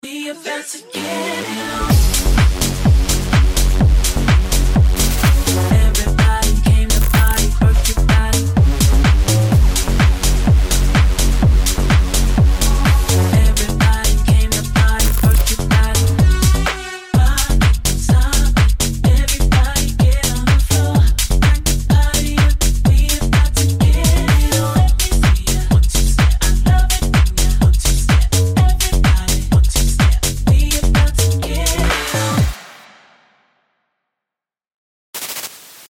Open format, can play any genres and style.